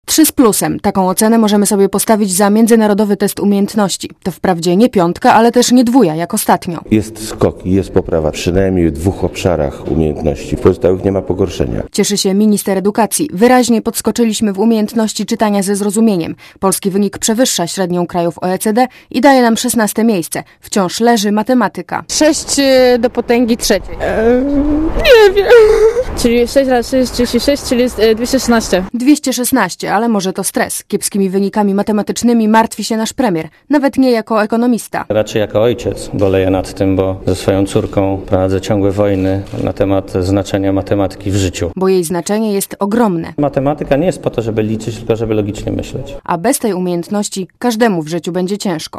Polskie nastolatki wypadły wprawdzie lepiej niż 3 lata temu, ale do ideału nam bardzo daleko 07.12.2004 | aktual.: 07.12.2004 18:13 ZAPISZ UDOSTĘPNIJ SKOMENTUJ Relacja reportera Radia ZET